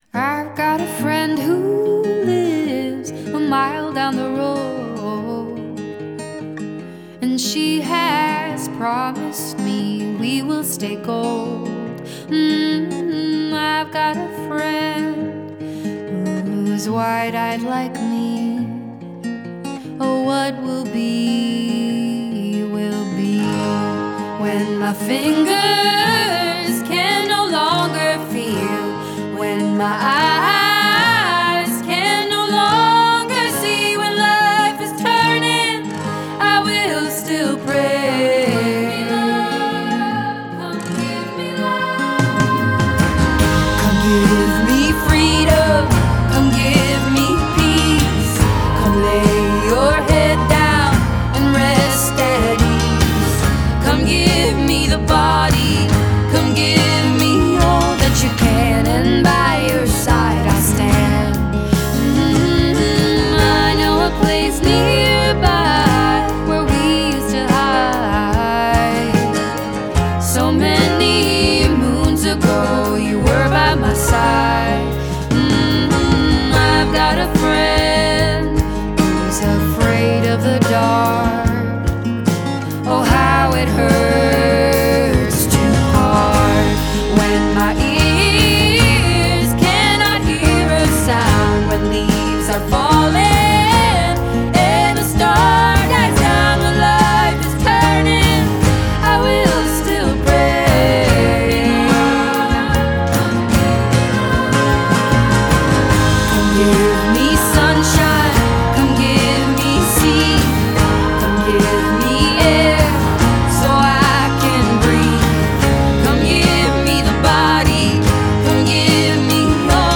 Жанр: Indie Pop
Genre - Indie Pop, Singer-Songwriter